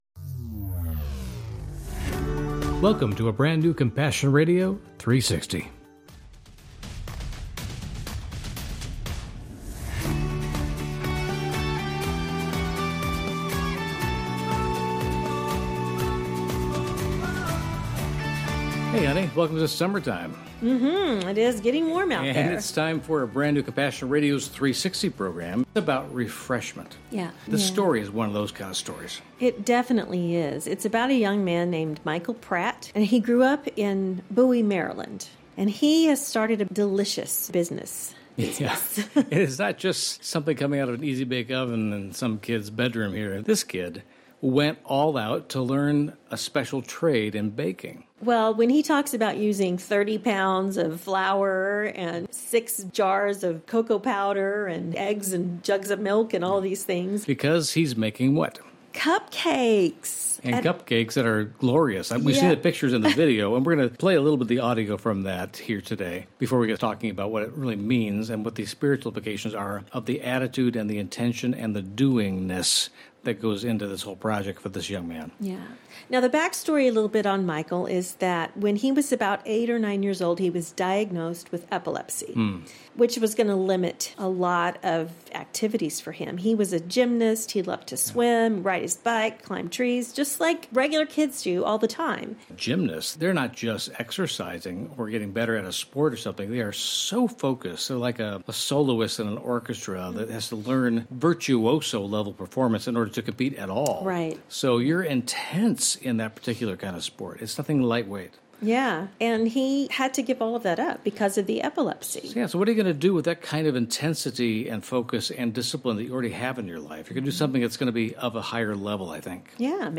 Through scripture, word and song, we’ll put a whole new spin on what’s happening in the world, right now. Today: What is the biggest ministry opportunity in YOUR community?